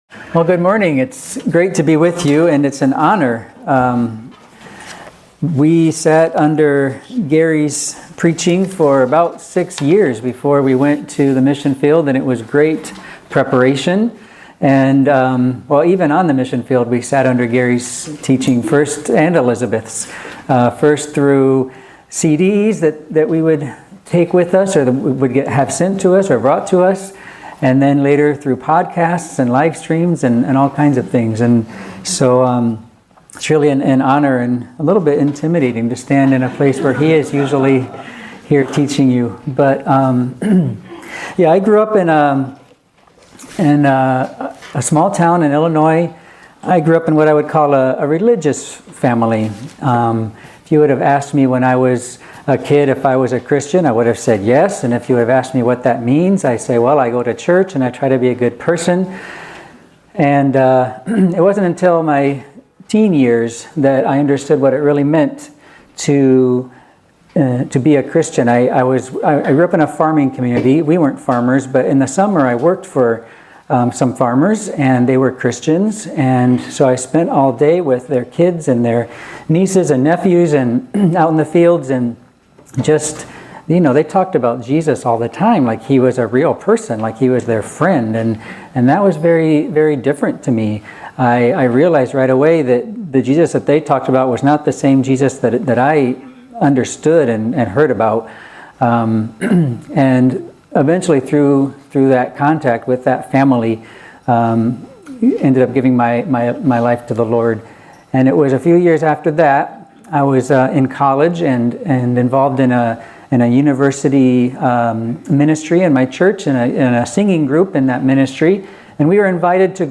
Download Audio Facebook Tweet Link Share Link Send Email More Messages Associated With " Standalone Sermon "...